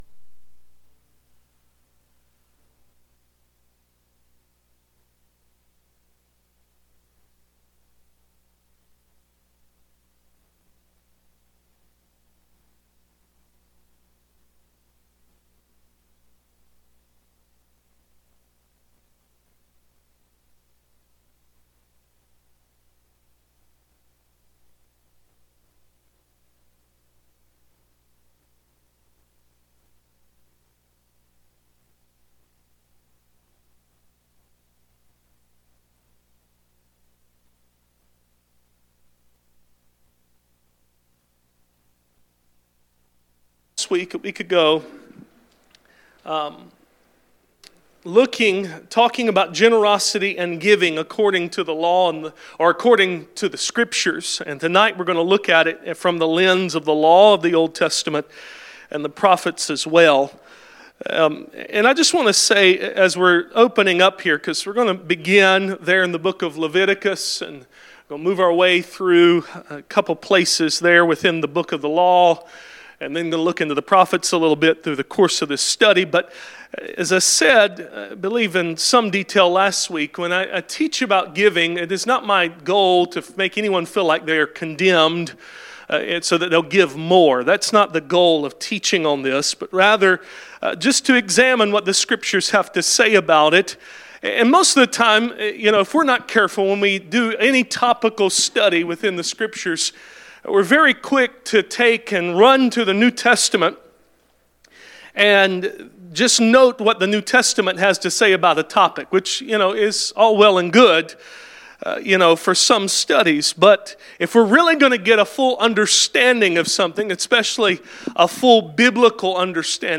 Passage: Proverbs 19:17 Service Type: Midweek Meeting « What do we need God to do with His word?